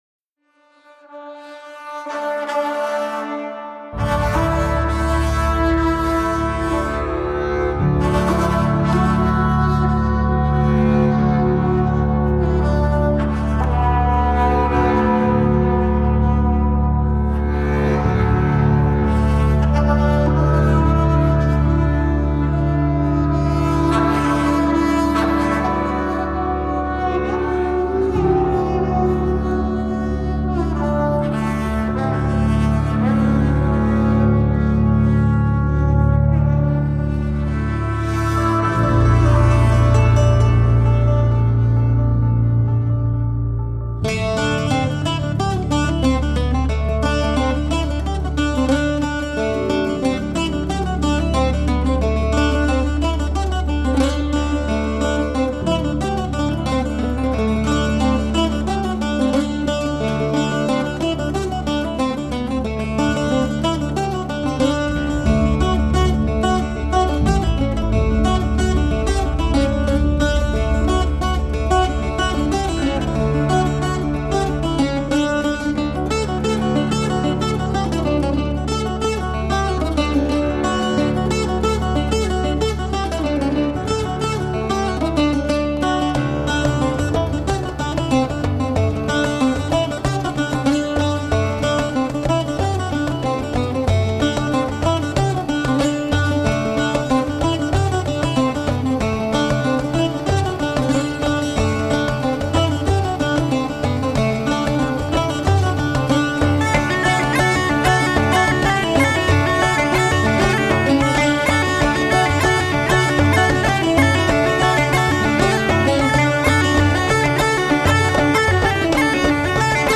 haunting slow airs to lively percussive reels and jigs